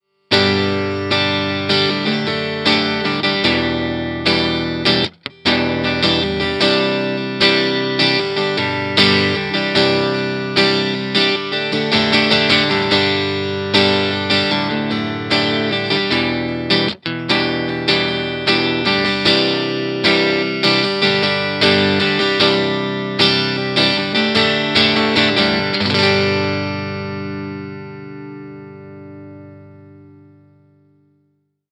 JTM45 Clean Tone Tubby Ceramic
JTM_CLEAN_ToneTubbyCeramic.mp3